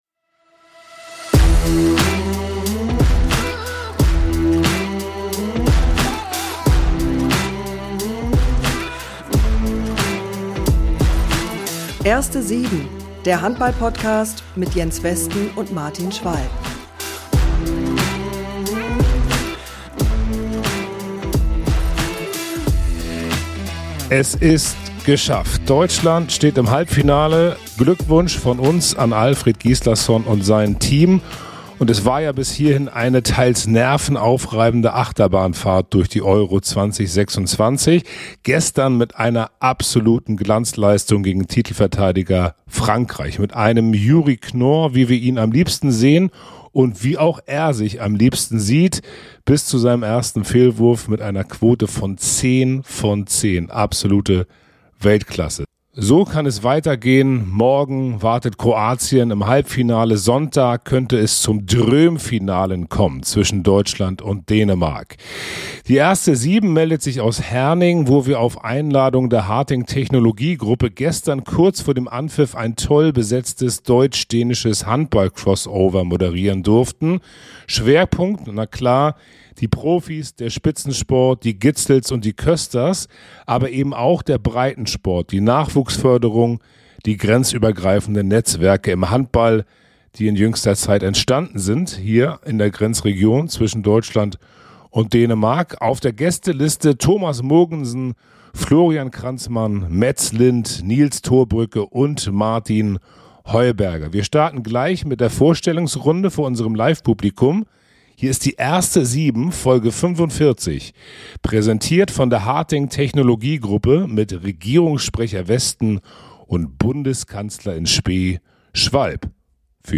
#045 Das Harting-EM-Special LIVE aus Herning ~ Erste 7 Podcast